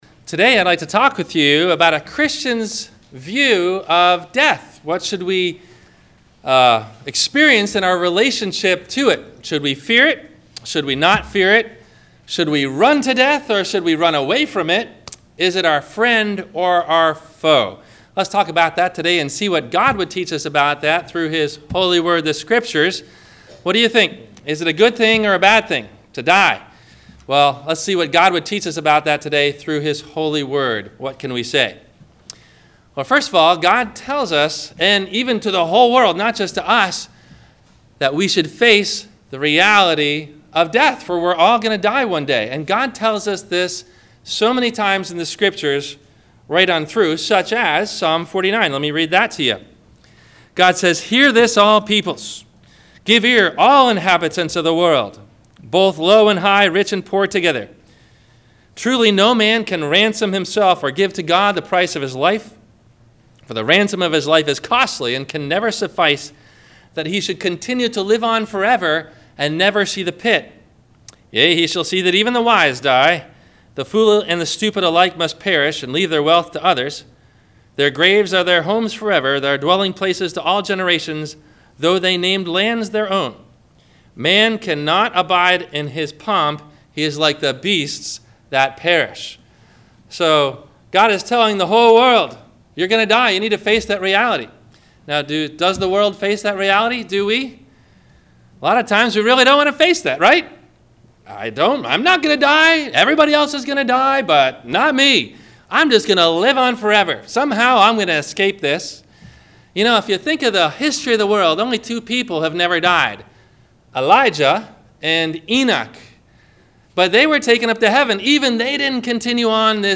Should a Christian Fear Death? - Sermon - April 23 2017 - Christ Lutheran Cape Canaveral